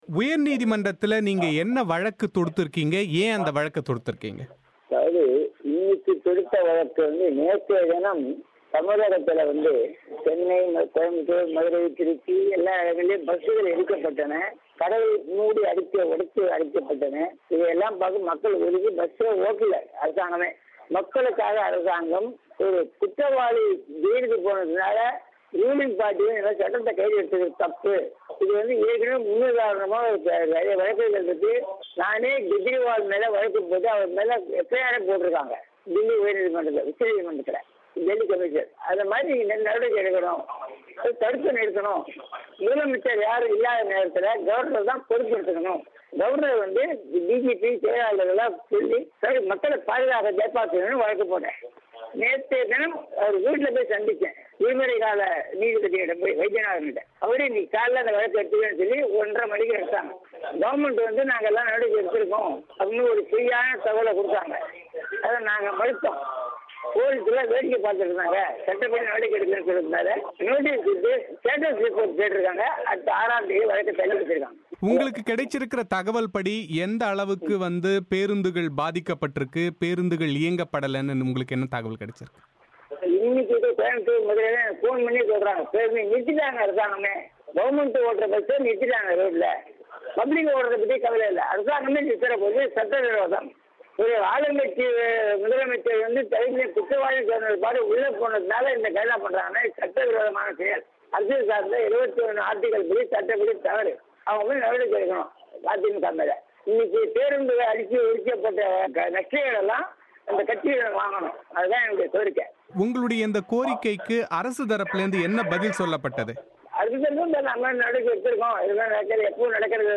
இந்த வழக்கு குறித்தும் இதில் அவர் முன்வைத்திருக்கும் முக்கிய கோரிக்கைகள் குறித்தும் டிராபிக் ராமசாமி பிபிசி தமிழோசக்கு அளித்த பிரத்தியேக செவ்வி.